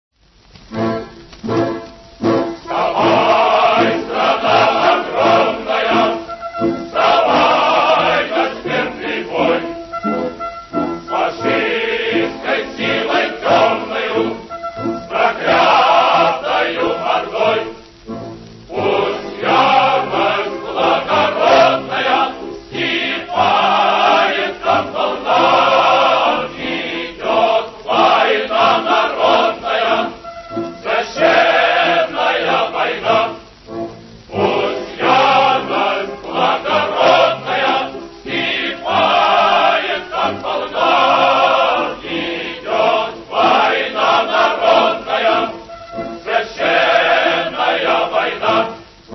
архивная запись со старой пластинки 1941 года